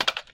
【効果音】何かをはめる音「カチャコ」 - ポケットサウンド - フリー効果音素材・BGMダウンロード
≫詳しくはこちら Paypal決済 カード決済 ※企業の方は こちら 素材のカテゴリ 物音 プラスチック 脱出ゲーム